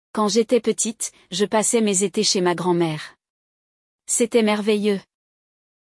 1. Ouça o diálogo com atenção – Perceba como as palavras são pronunciadas e como as frases são construídas.